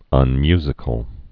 (ŭn-myzĭ-kəl)